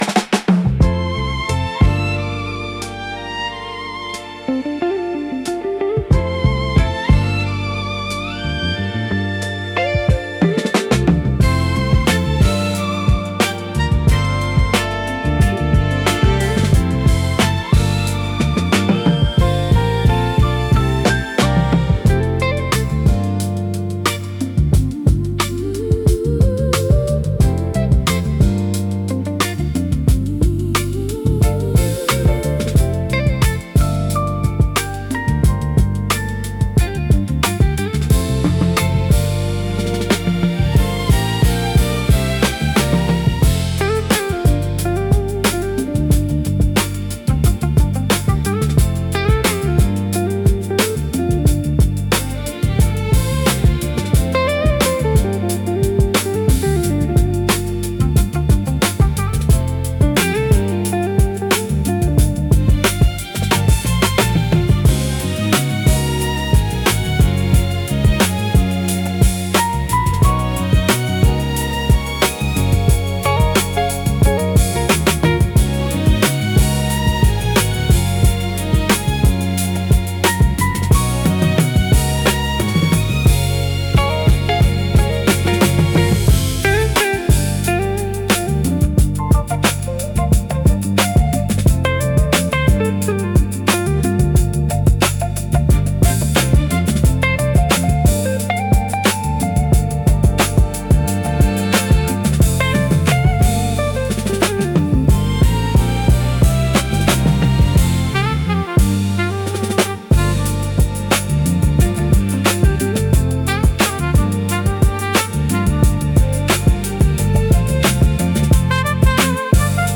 Soul, Vintage, Hip Hop, Elegant, Positive